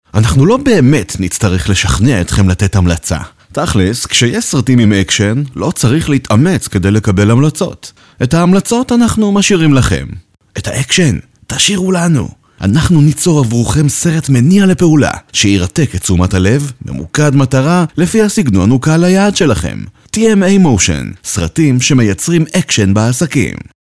למשל לסרטון פרסומי אני ממליץ על הקריין